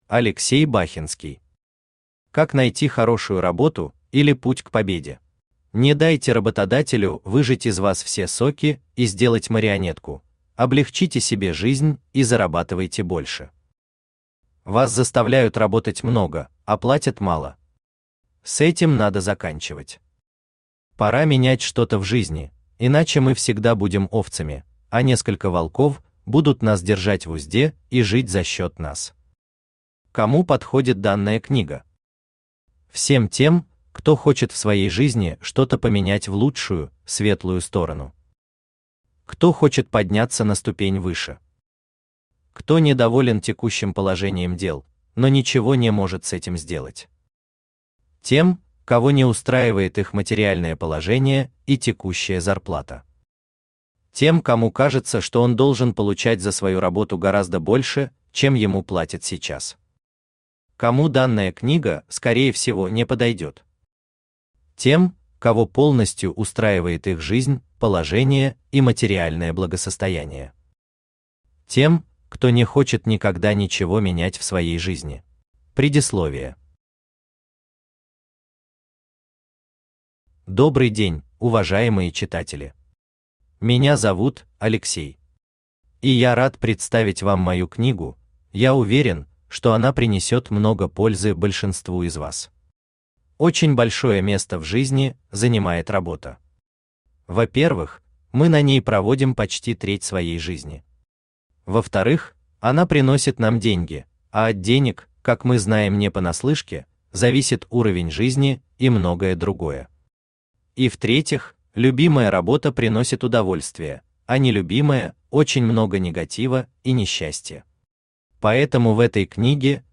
Автор Алексей Бахенский Читает аудиокнигу Авточтец ЛитРес.